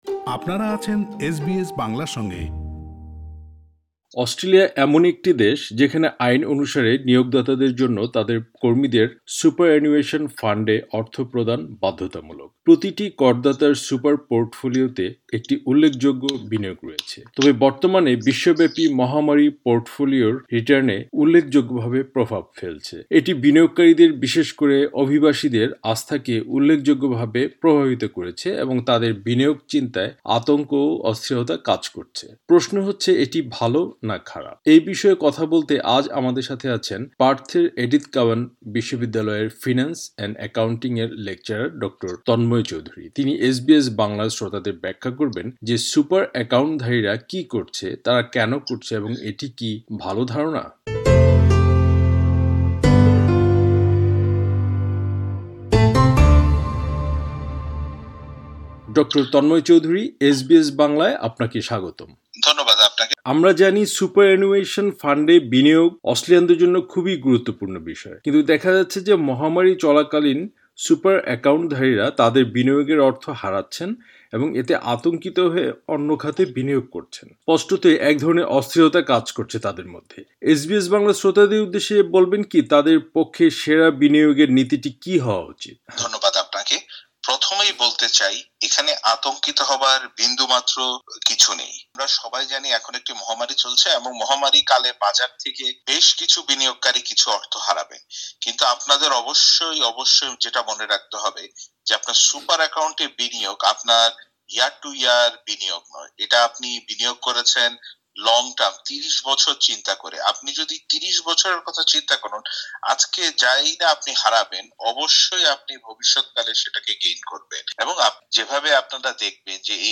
কভিড ১৯ মহামারীর এই সময়ে সুপার ফান্ডসহ বিনিয়োগ নিয়ে কথা বলতে আমাদের সাথে আছেন পার্থের এডিথ কাউয়ান বিশ্ববিদ্যালয়ের